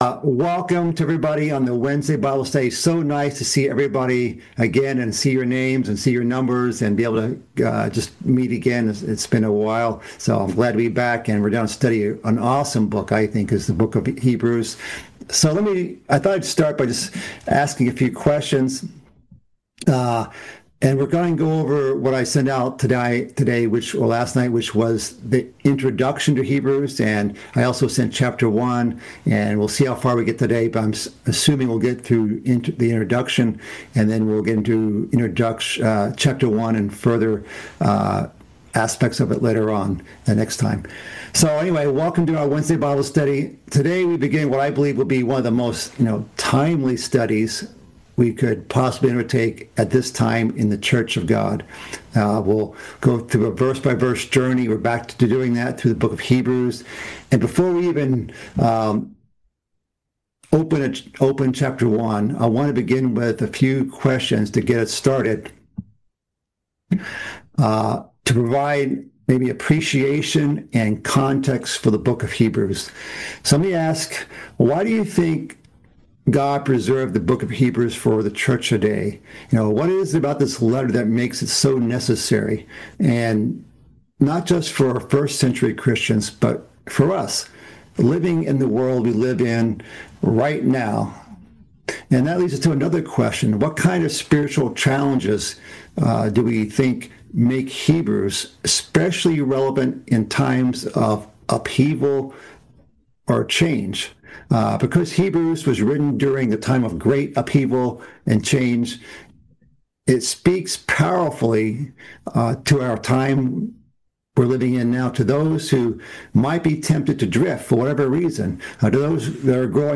Bible Study - Hebrews Part 1 - Introduction